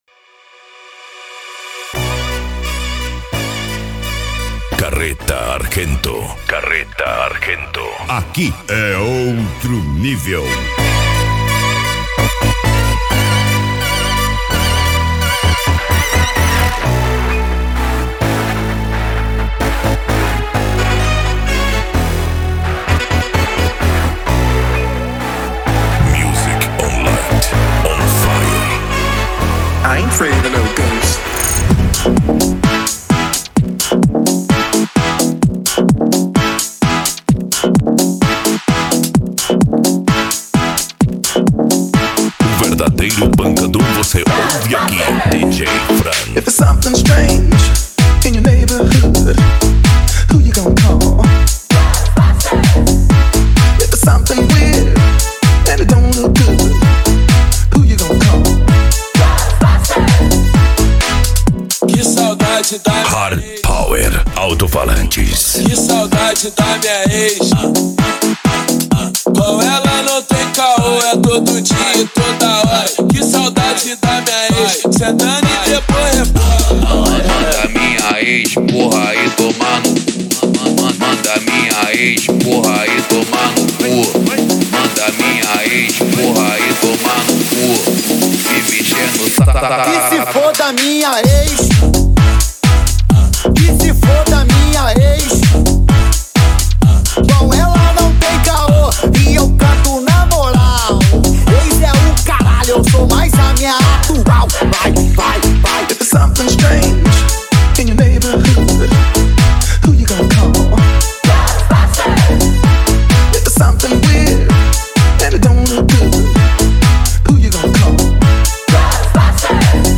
Bass
Euro Dance
Pagode
Remix